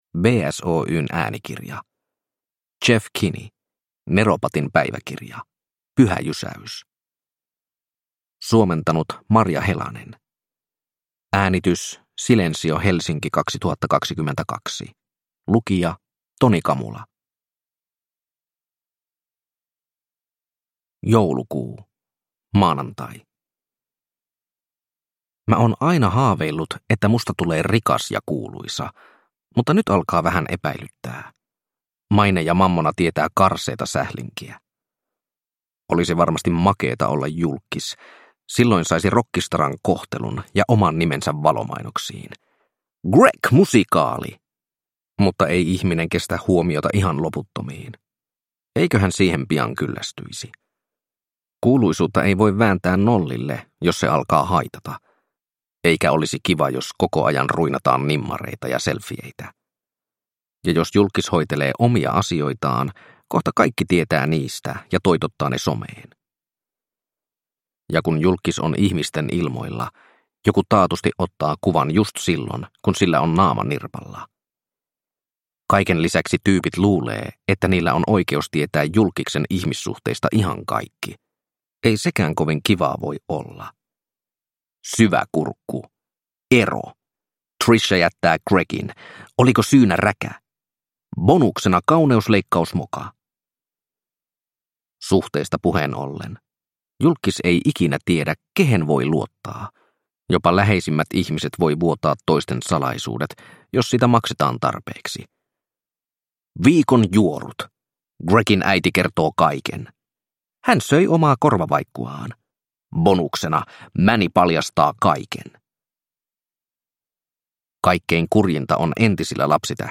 Neropatin päiväkirja: Pyhä jysäys – Ljudbok – Laddas ner